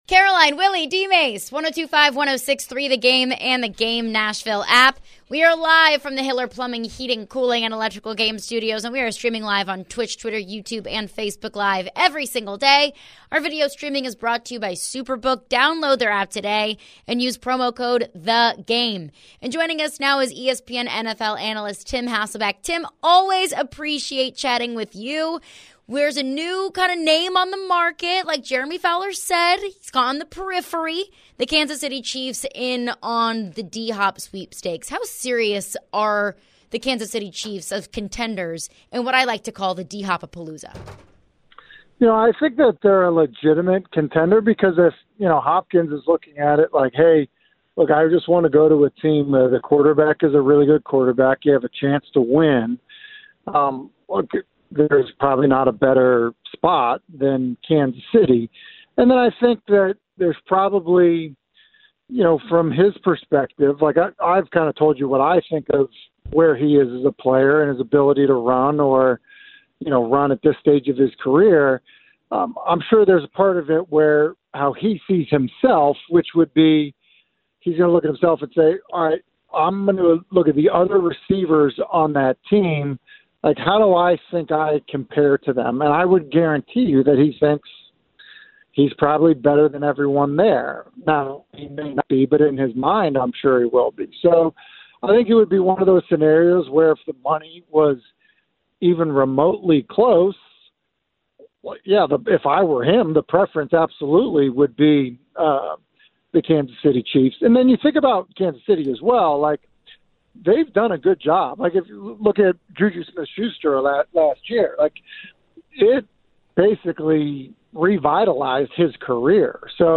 Tim Hasselbeck joins the show and talks about the possibility of the Titans signing DeAndre Hopkins. Could the Chiefs swoop in and sign Hopkins at the last minute? Later in the interview, Tim gives his input on the Northwestern football hazing situation.